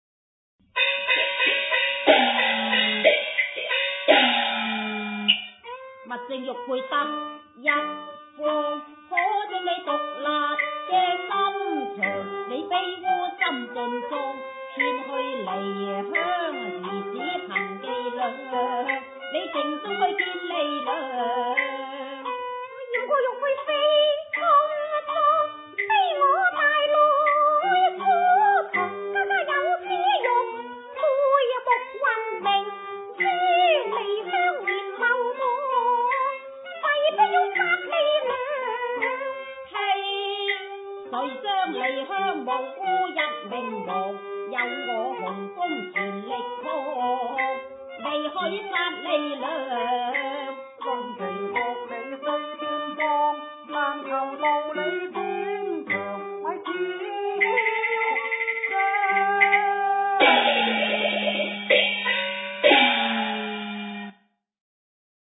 選段以“先鋒查”鑼鼓點開始，說白後有“齊槌”鑼鼓。第六小節二幫花說白前，有“三批”接“順三槌”鑼鼓點。選段再以“先鋒查”完結，結尾部份漸慢。
粵劇示範